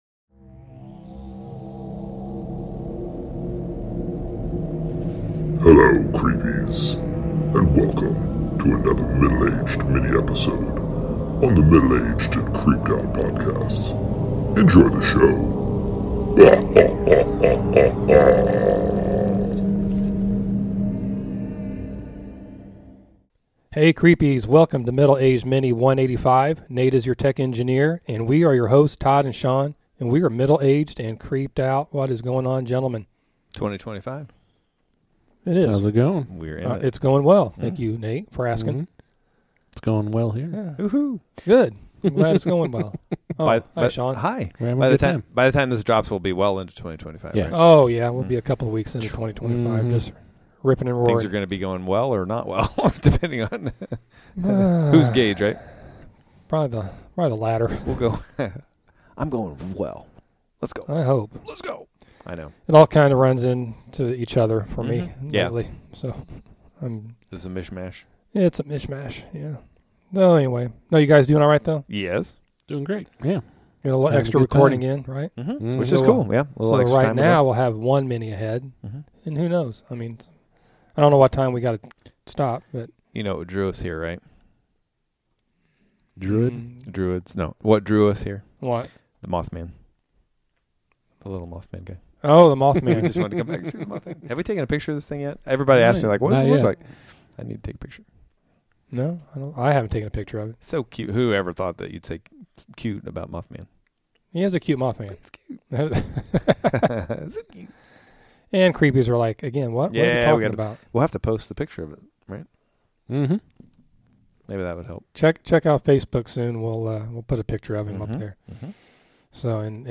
The guys have a "light" but horrifying discussion on these mythological and legandary elemental creatures...Fairies!!!